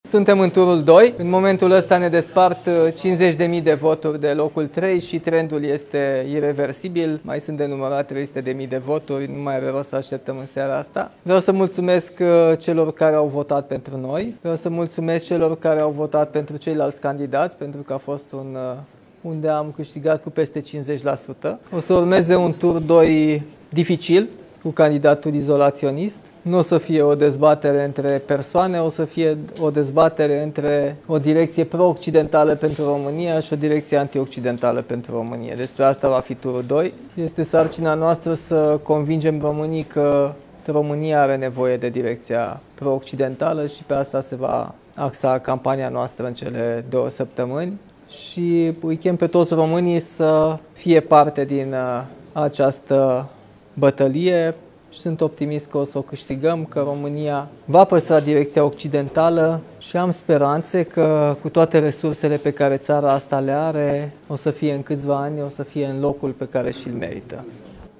Primarul Capitalei, Nicuşor Dan, candidat independent la alegerile prezidenţiale, anticipează că turul al doilea, în care se va confrunta cu liderul AUR George Simion, va fi unul “dificil”. El a făcut declarații azi noapte la sediul de campanie în aplauzele și scandările susținătorilor.